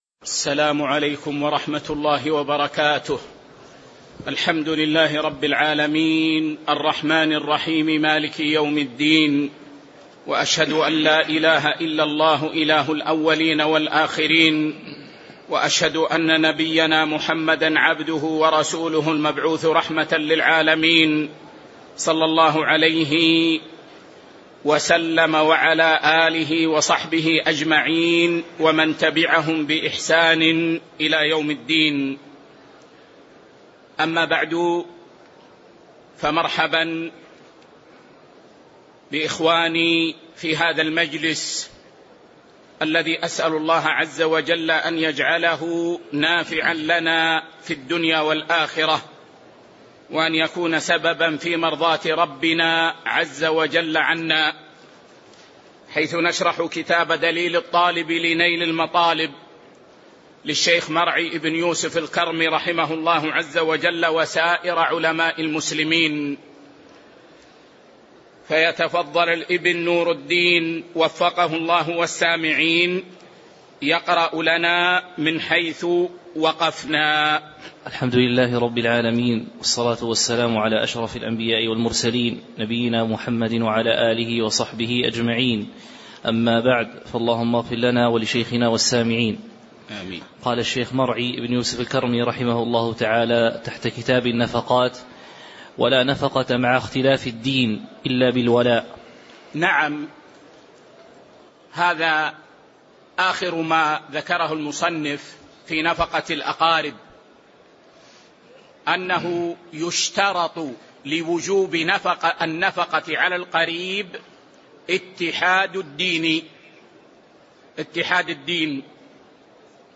شرح دليل الطالب لنيل المطالب الدرس